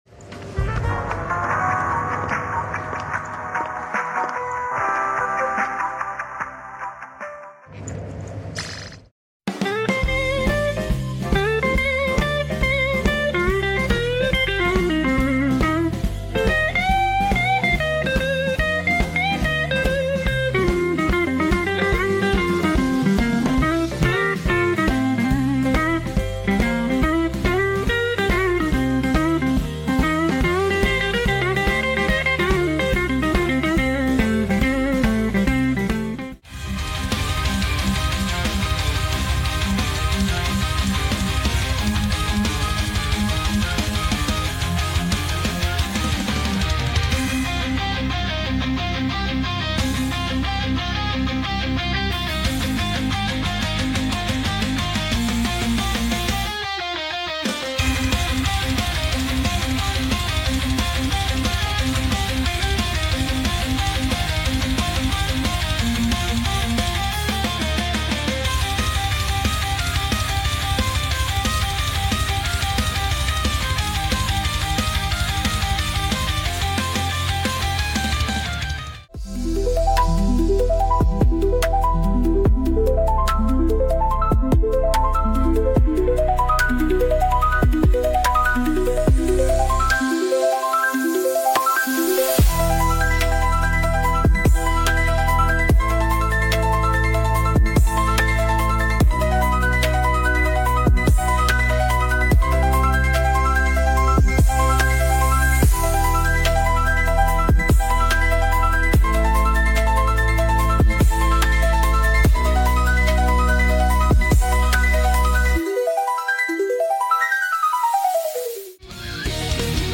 Driver Disk Music Using AI